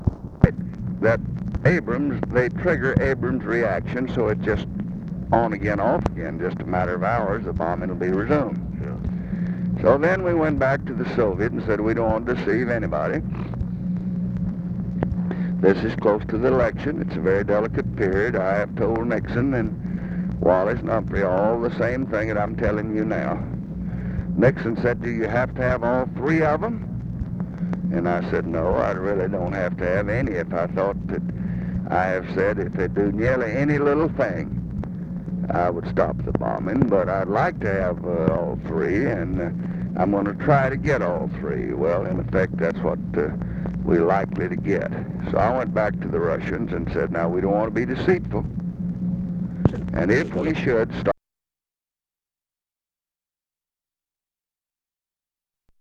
Conversation with EVERETT DIRKSEN, October 31, 1968
Secret White House Tapes